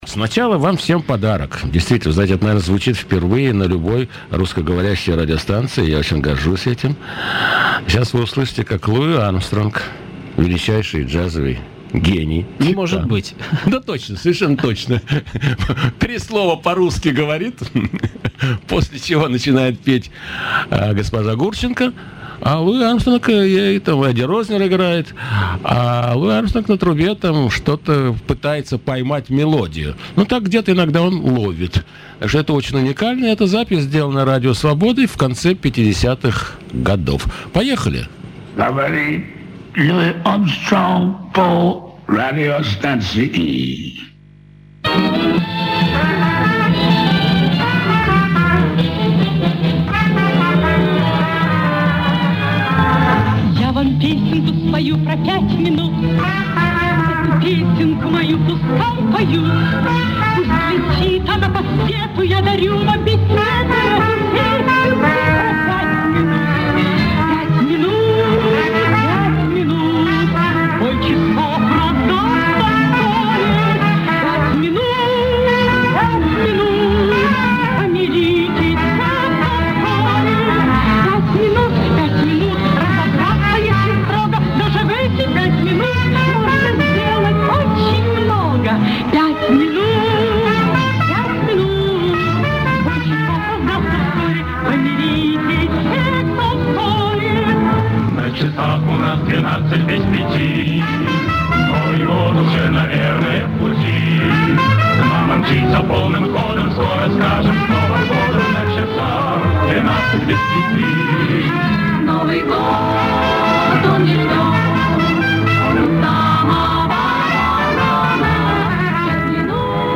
Извините за низкое качество...